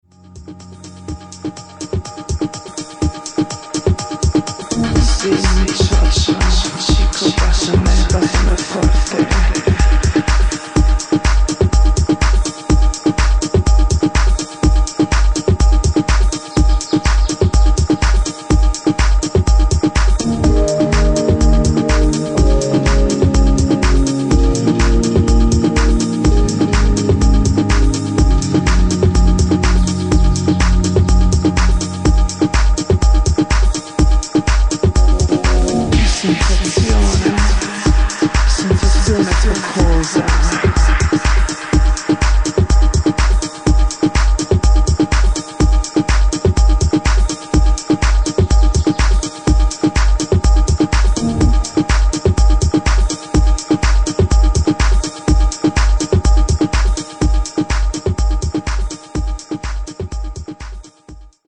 deep trippy house with nice layers of strings